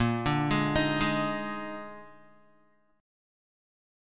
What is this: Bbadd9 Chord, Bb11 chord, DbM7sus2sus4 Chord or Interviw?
Bb11 chord